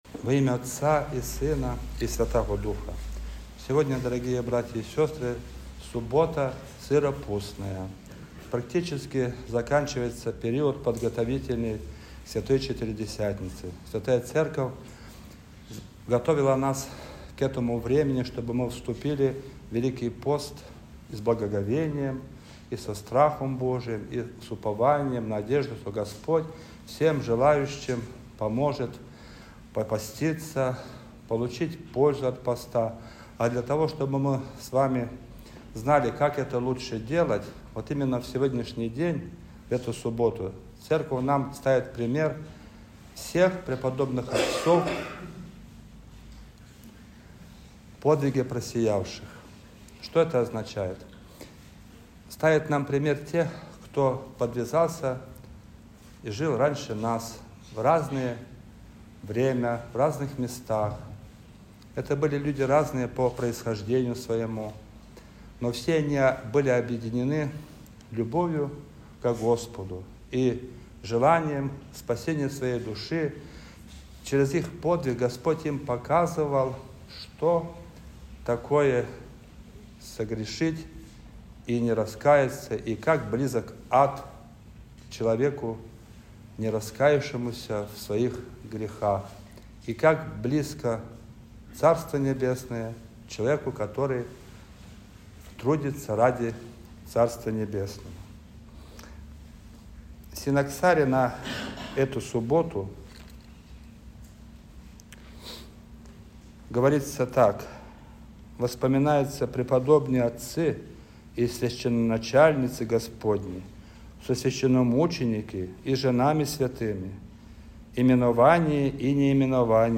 Проповедь
Суббота-сырной-седмицы.mp3